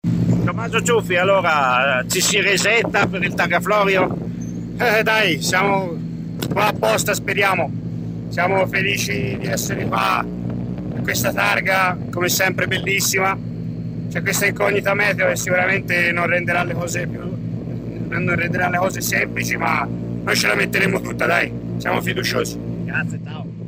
Interviste 106° Targa Florio
Interviste pre-gara